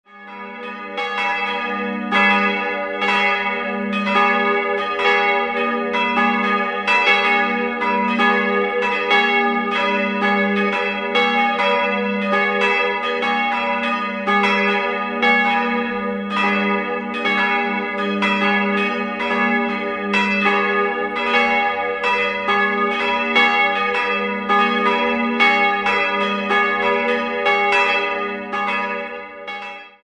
An die Südseite wurde 1977 ein neues Kirchenschiff angebaut, so dass das Gotteshaus nun einen etwa L-förmigen Grundriss aufweist. 3-stimmiges Gis-Moll-Geläute: gis'-h'-dis'' Das Euphongeläute stammt aus dem Jahr 1950 von Karl Czudnochowsky (Erding) und erklingt exakt in den Tönen gis'-3, h'-2 und dis''-2.